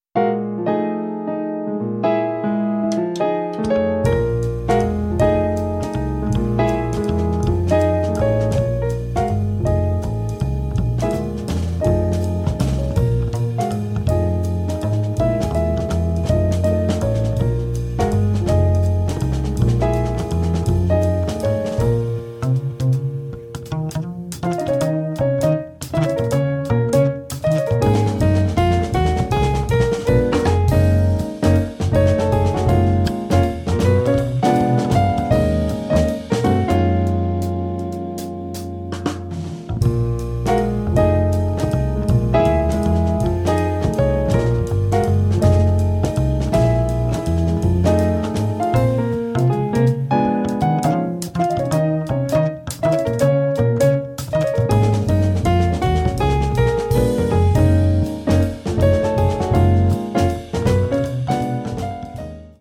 piano
contrabbasso e basso elettrico
batteria